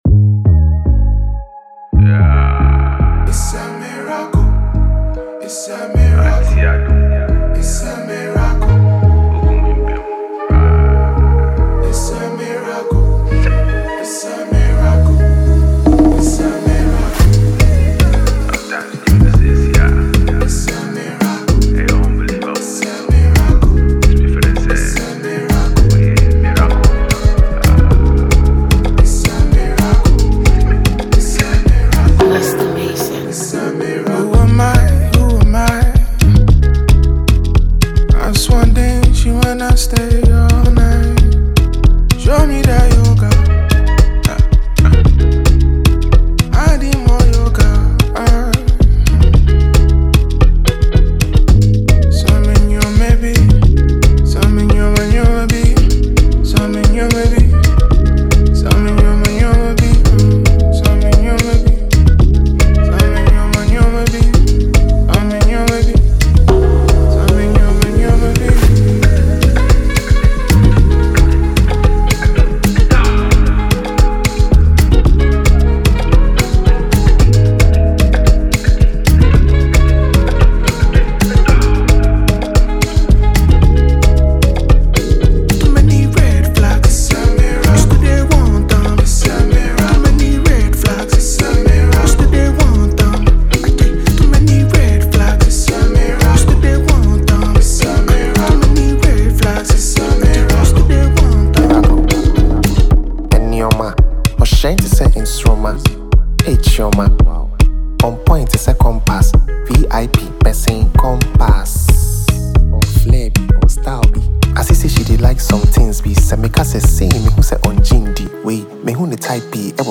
lively and energetic Ghanaian hit
catchy flows, rhythmic beats, and a playful energy
• Genre: Hip-Hop / Afrobeats / Dancehall